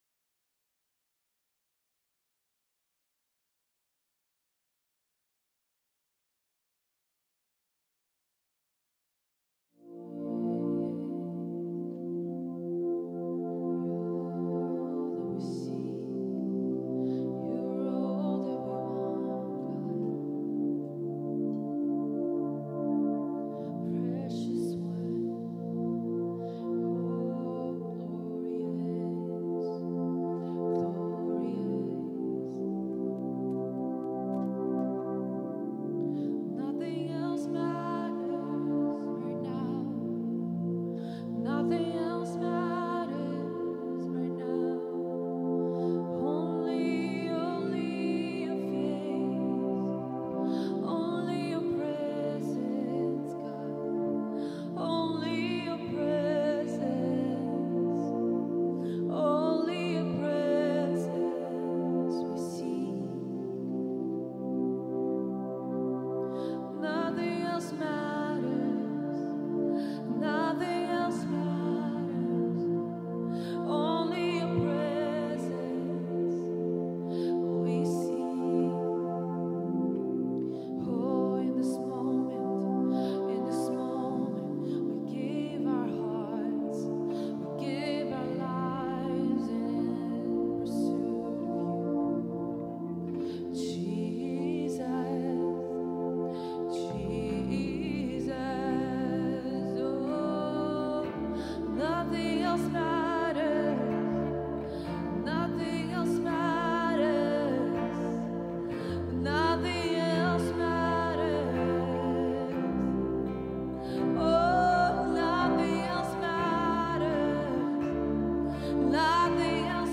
Прославление Центра Трансформации / TC Band Live Worship (September 15, 2019) - 15 Сентября 2019 - Главная Страница - Transformation Center